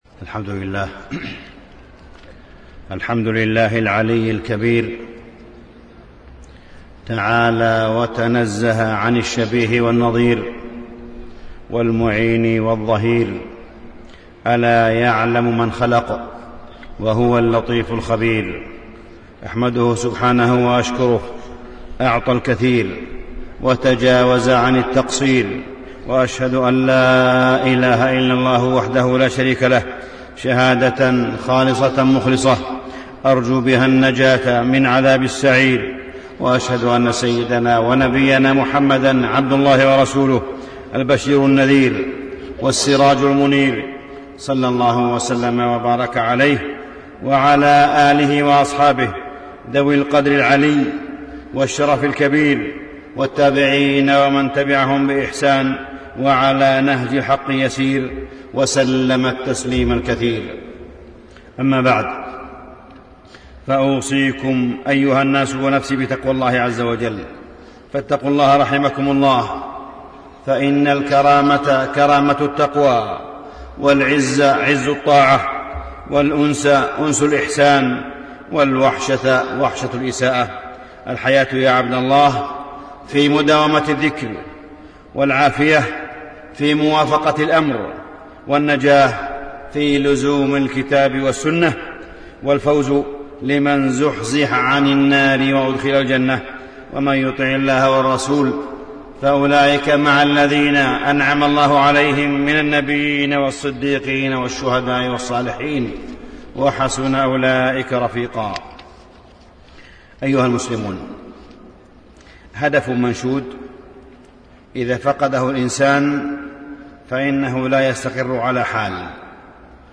تاريخ النشر ٦ جمادى الآخرة ١٤٣٣ هـ المكان: المسجد الحرام الشيخ: معالي الشيخ أ.د. صالح بن عبدالله بن حميد معالي الشيخ أ.د. صالح بن عبدالله بن حميد نعمة الرضا